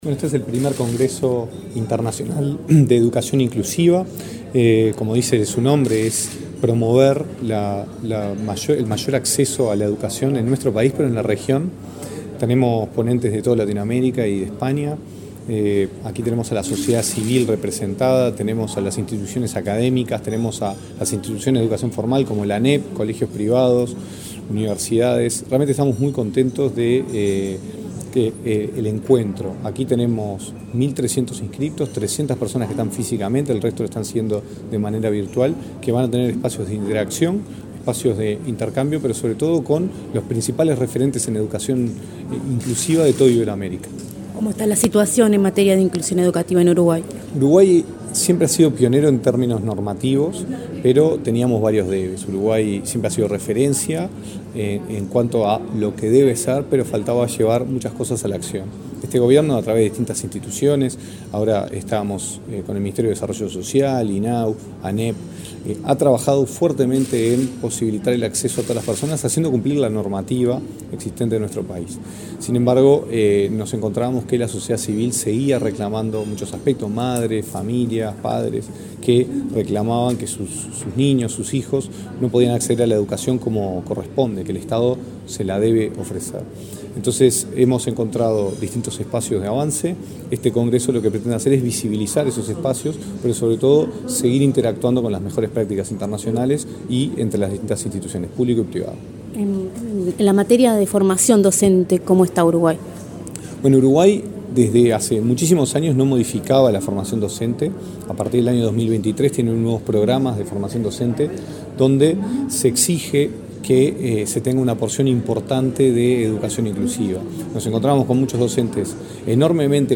Entrevista al director nacional de Educación, Gonzalo Baroni
Entrevista al director nacional de Educación, Gonzalo Baroni 11/10/2023 Compartir Facebook X Copiar enlace WhatsApp LinkedIn El director nacional de Educación, Gonzalo Baroni, dialogó con Comunicación Presidencial, este miércoles 11 en Montevideo, luego de participar en el primer congreso internacional sobre educación inclusiva, organizado por el Ministerio de Educación y Cultura.